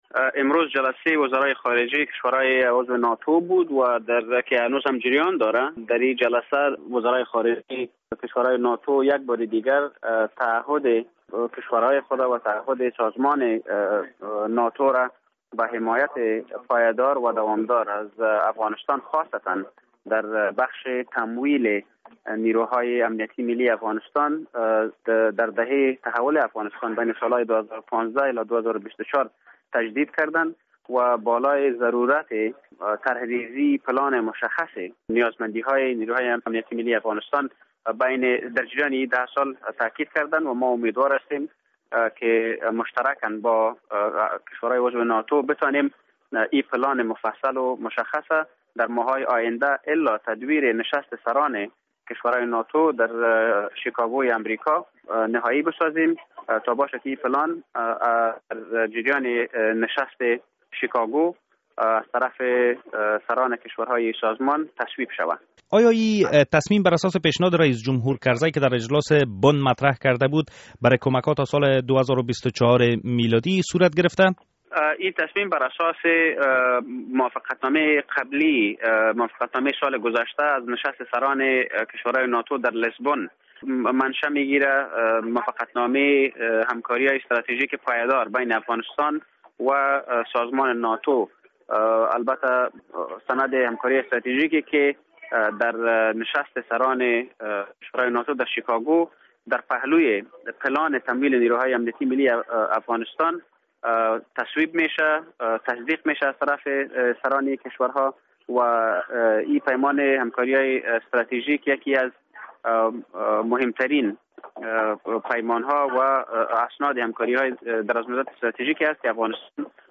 مصاحبه با جانان موسی زی در مورد تعهد کشورهای عضو ناتو به افغانستان